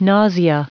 Prononciation du mot nausea en anglais (fichier audio)
Prononciation du mot : nausea
nausea.wav